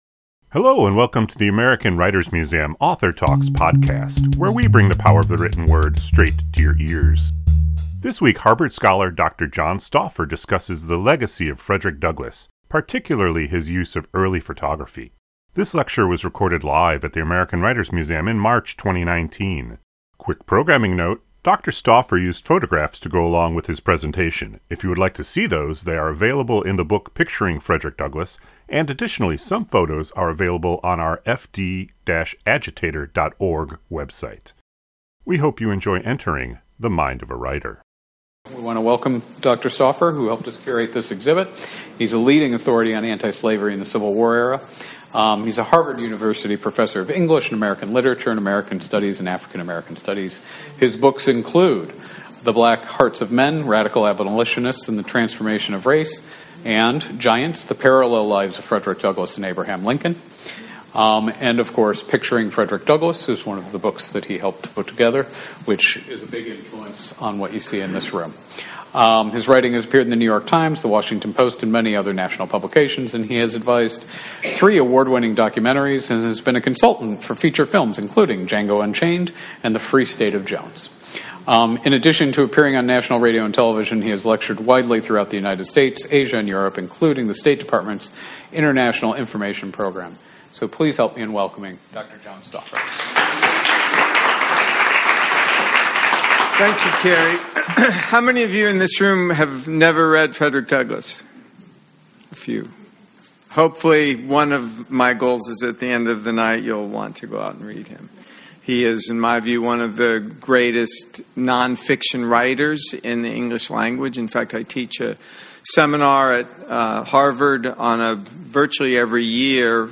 This lecture was recorded live at the American Writers Museum in March 2019.